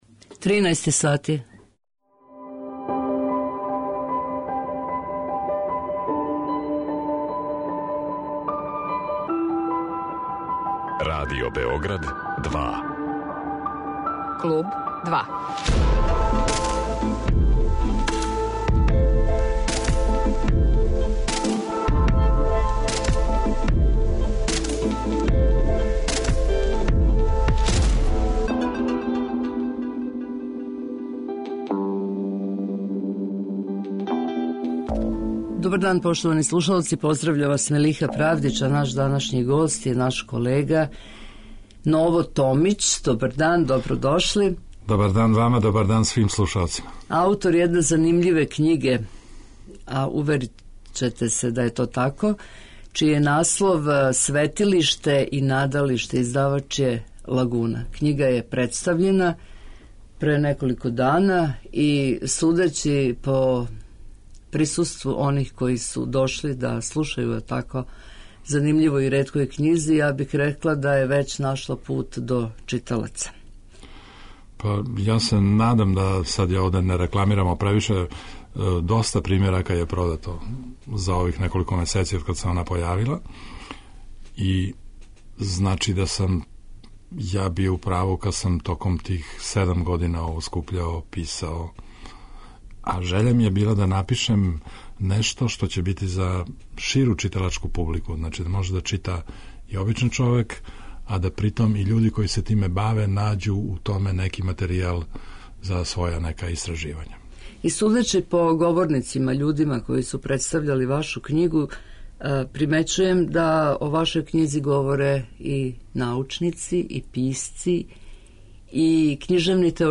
Гост Клуба 2